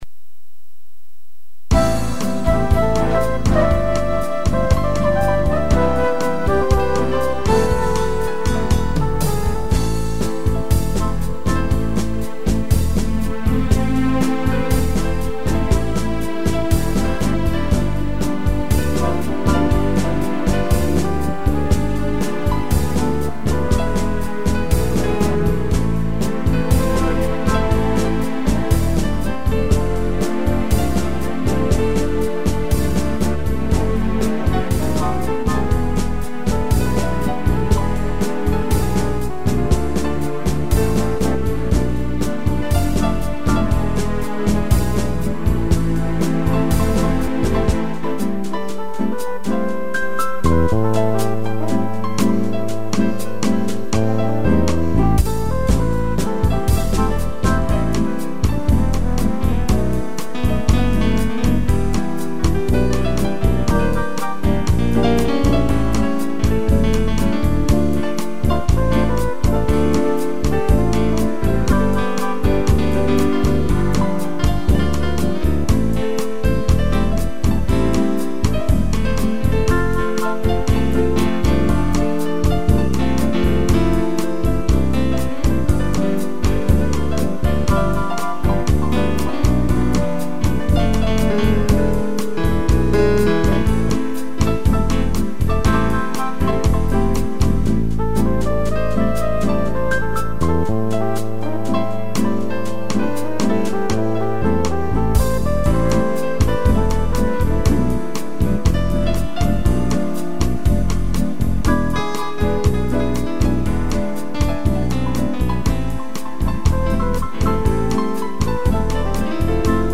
piano e flugel_horn
(instrumental)